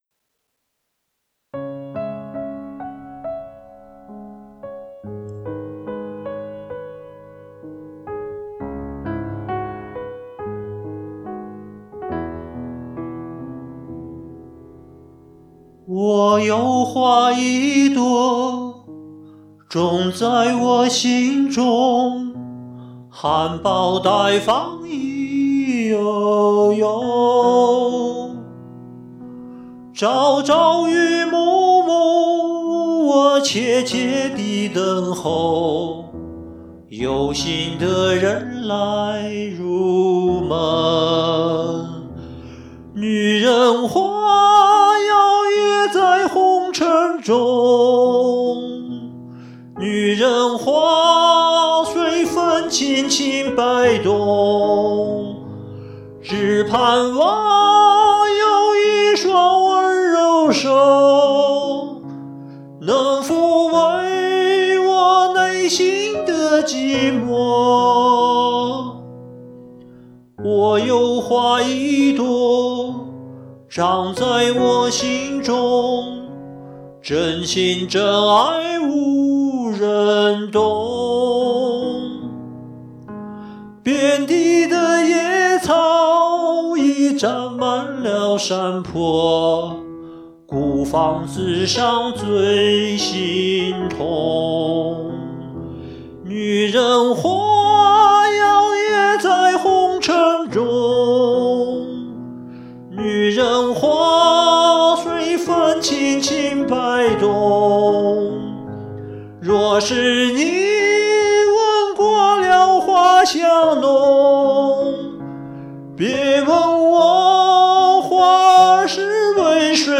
这是高音E版，感觉情感有点高扬了